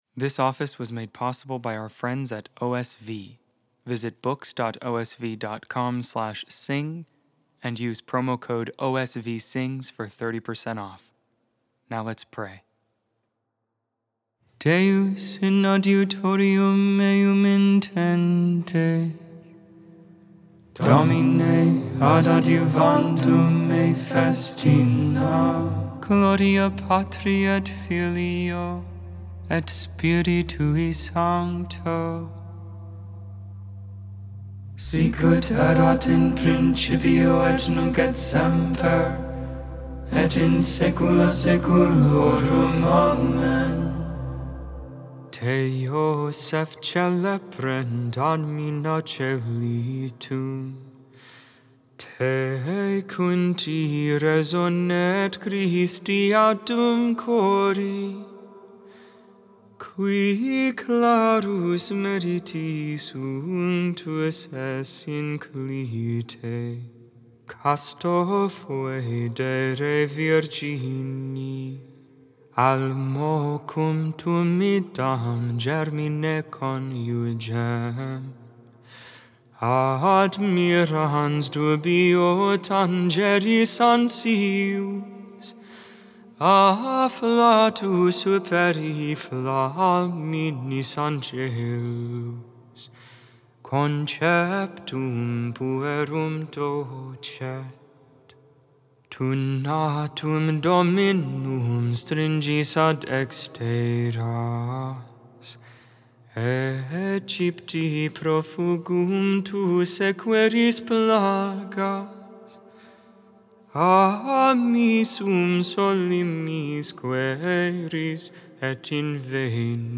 And OSV carries a variety of study Bibles and Catechisms to equip young adults as they progress in their faith formation._____All antiphons and responsory transcribed for English from the Gregorian by Sing the Hours.